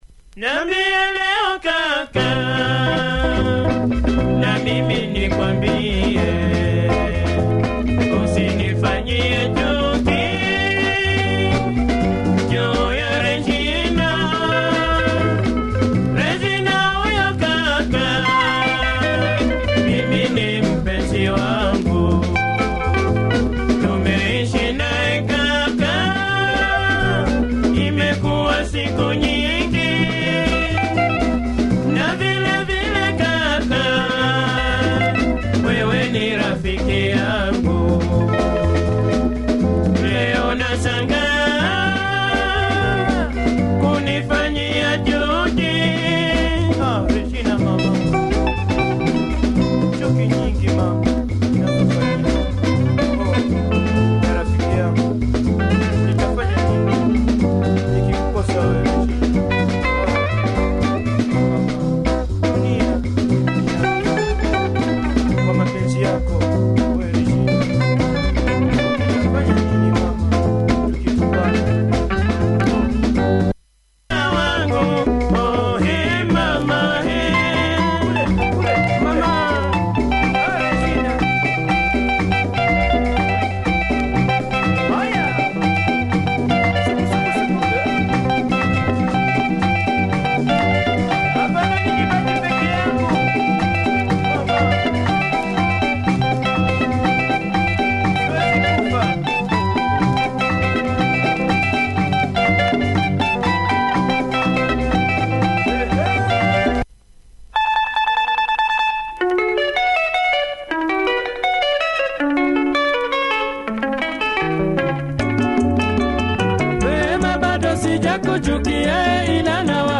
Great melody on this one, long breakdown on the B-side.